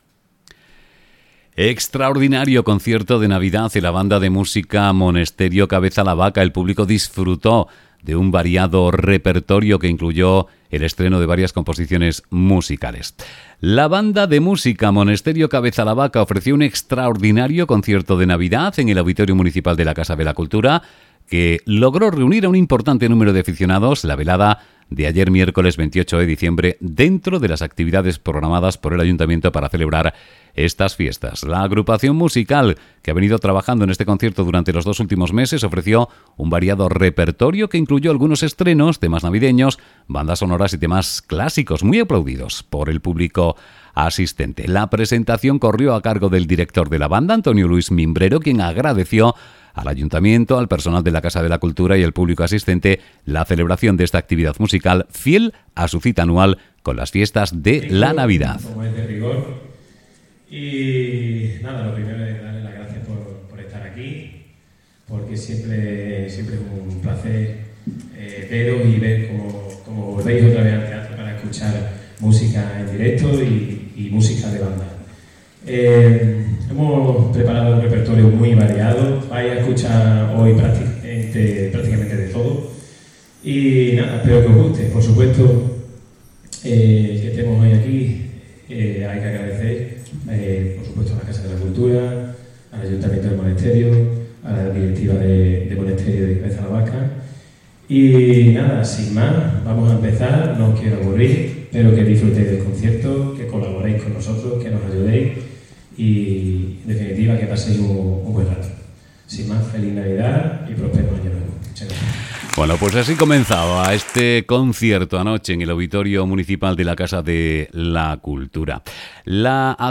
Extraordinario concierto de Navidad de la banda de música Monesterio – Cabeza la Vaca